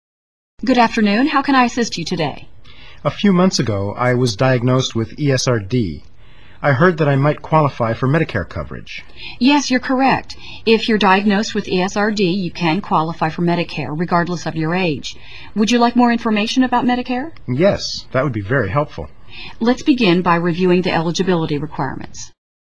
Select the Audio icon to hear part of their phone conversation or select the Text icon to read the transcript of their call.